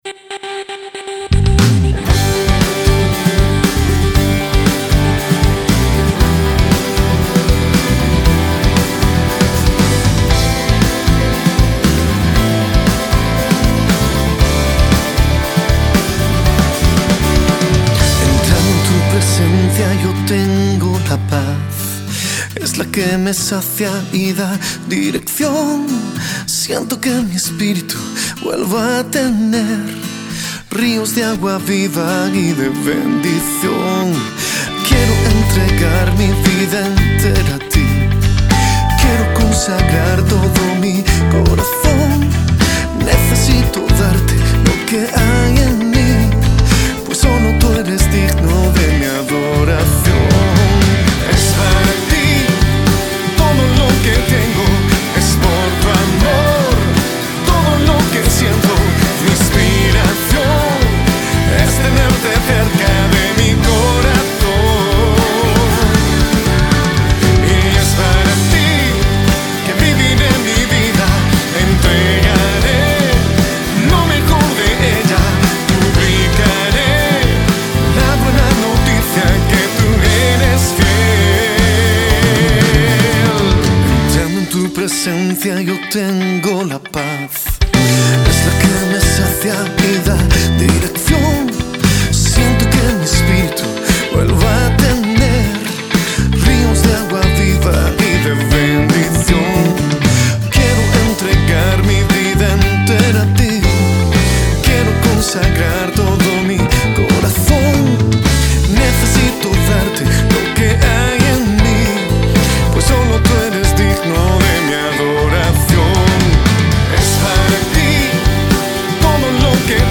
Guitarras:
Saxo:
Voz Principal Grabada en